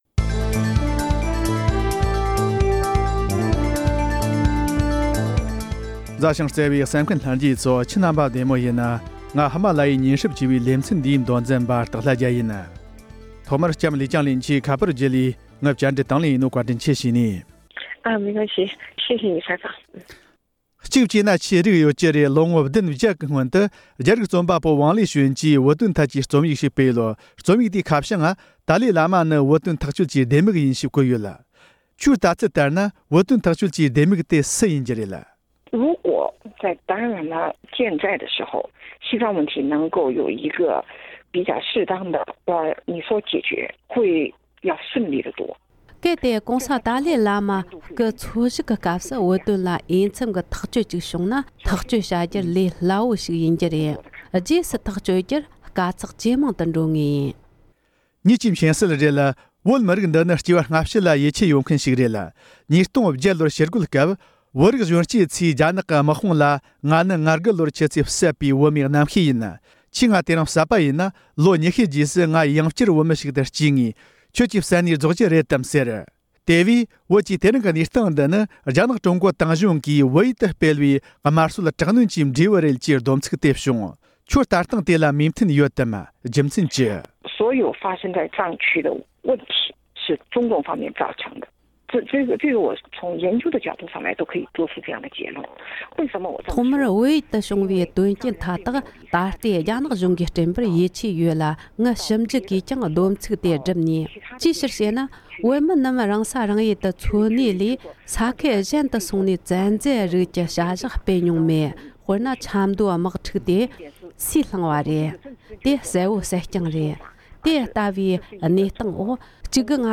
རྒྱ་ནག་དེང་གཞུང་གིས་བོད་ཡུལ་དུ་བསགས་པའི་ཉེས་པ་དང་གསང་བའི་དམག་འཐབ་སྤེལ་བ་ཁག་ཐེར་འདོན་བྱེད་དོན་སོགས་ཀྱི་ཐད་བཅར་འདྲི།